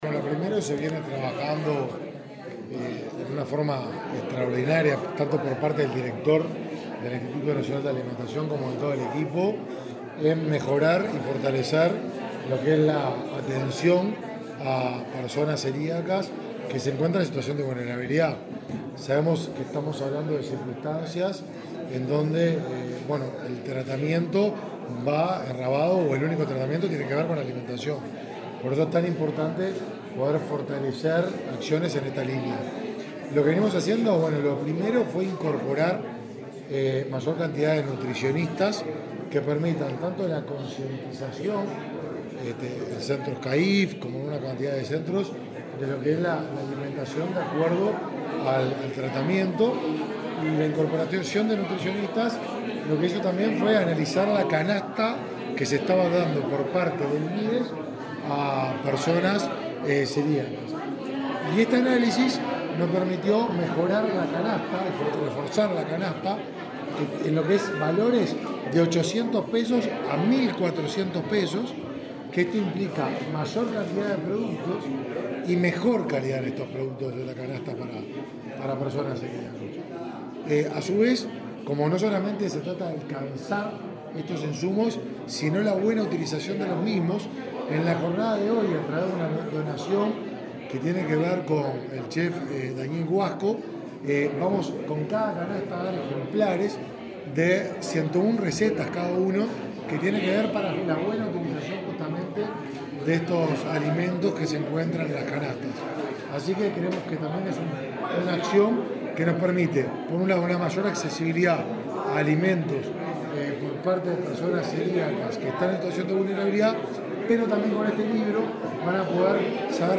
Declaraciones a la prensa del ministro de Desarrollo Social, Martín Lema
En el marco del Día Nacional del Celíaco, el presidente del Instituto Nacional de Alimentación (INDA), Ignacio Elgue, y el ministro de Desarrollo Social, Martín Lema, destacaron, este jueves 5 en Rocha, el trabajo de esa cartera para atender a la población vulnerable que sufre esa patología. Luego, el titular de la secretaría de Estado dialogó con la prensa.